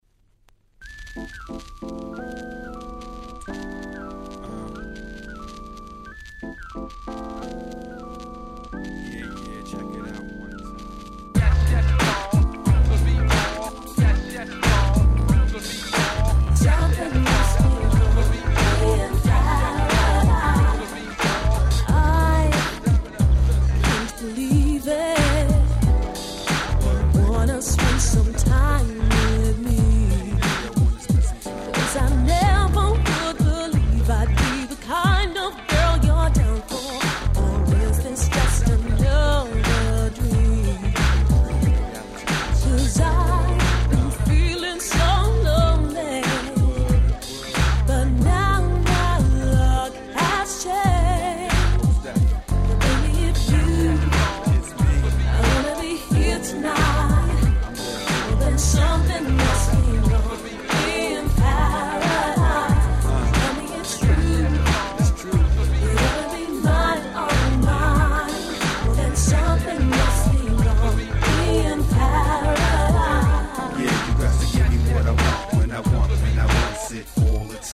95' NiceマイナーUK R&B !!